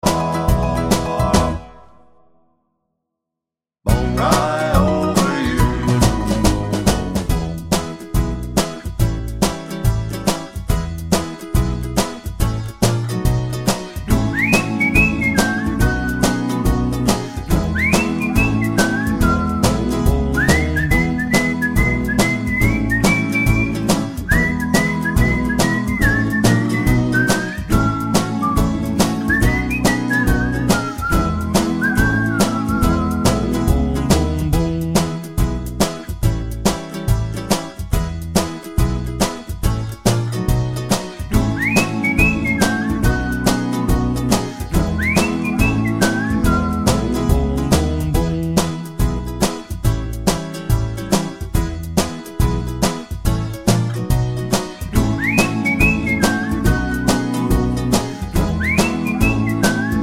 no whistle Pop (1950s) 2:28 Buy £1.50